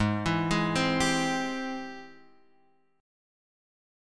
G# Augmented Major 7th